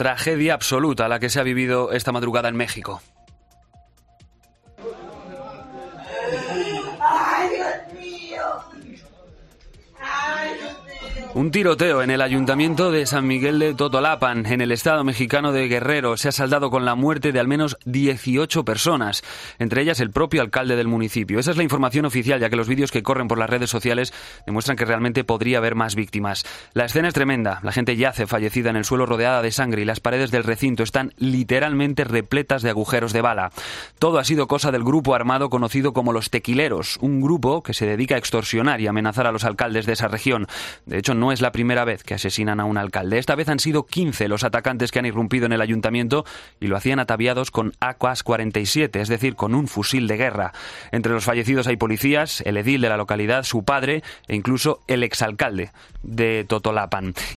Última hora sobre el ataque de un grupo armado en México